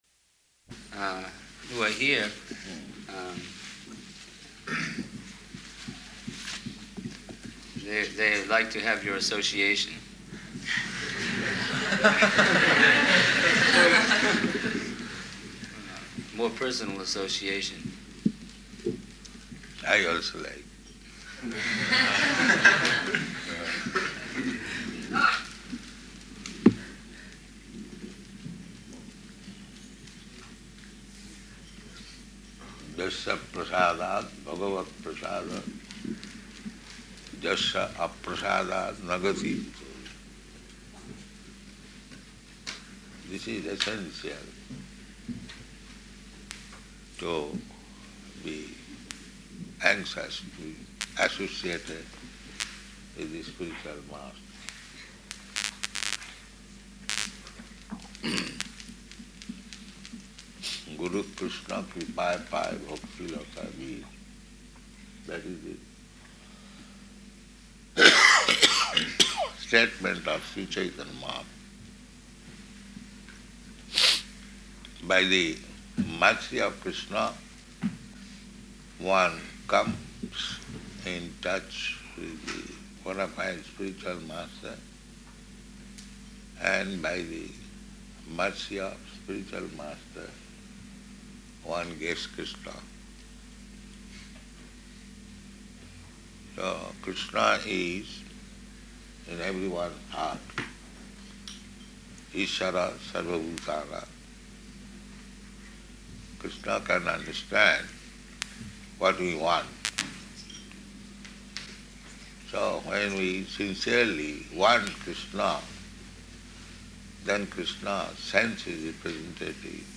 Room Conversation with Two Lawyers and Photographer
Type: Conversation
Location: Melbourne